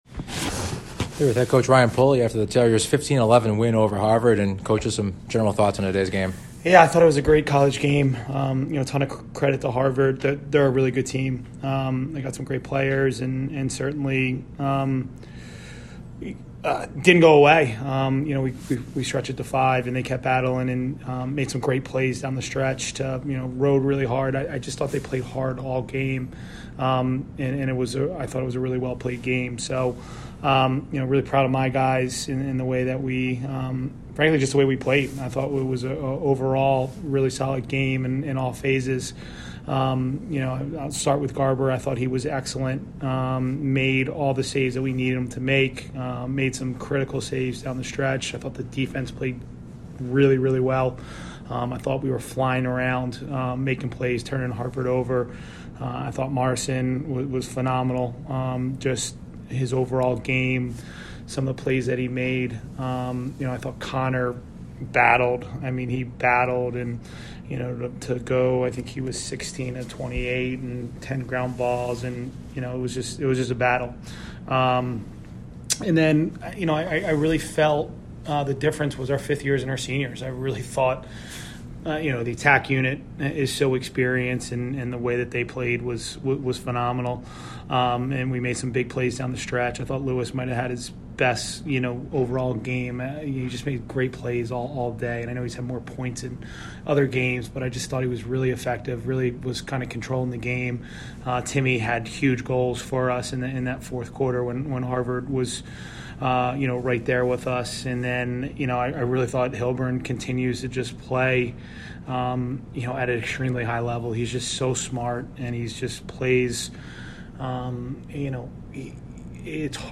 Men's Lacrosse / Harvard Postgame Interview